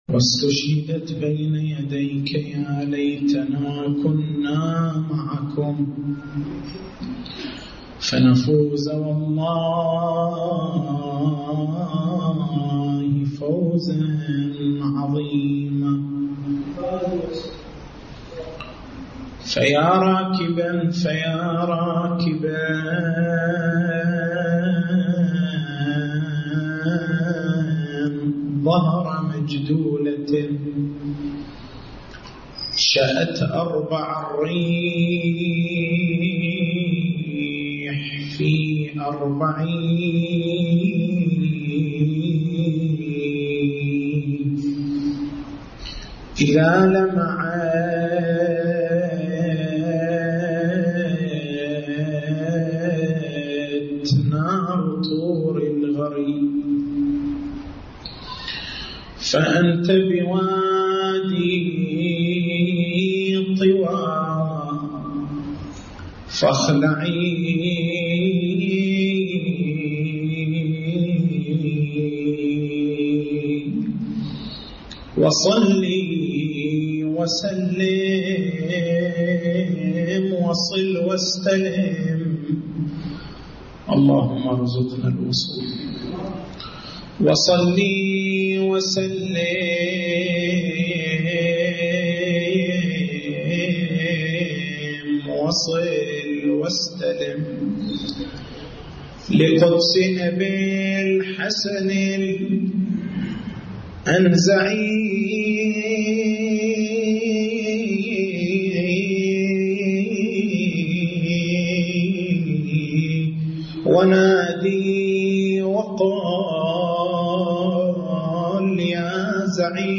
تاريخ المحاضرة: 28/09/1431 نقاط البحث: معنى البداء في اللغة الفرق بين عالم التكوين وعالم التشريع هل النسخ والبداء يستلزمان نسبة الجهل إلى الله تعالى؟